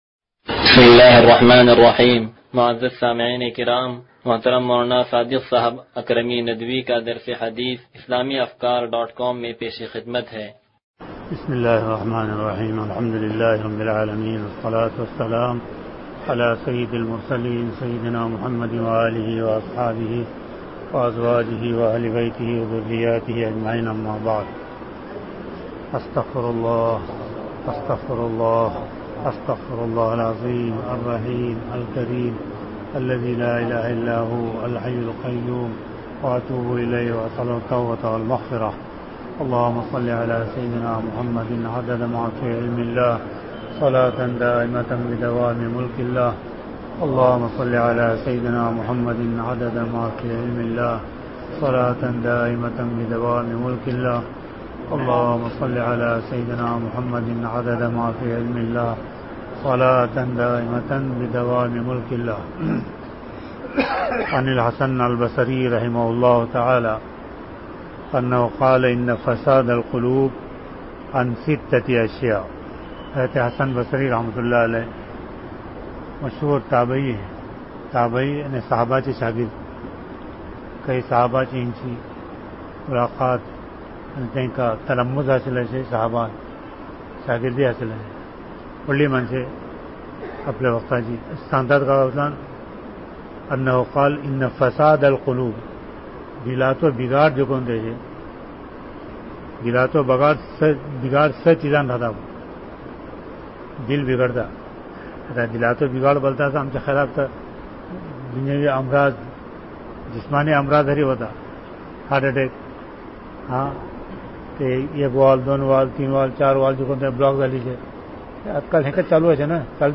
درس حدیث نمبر 0165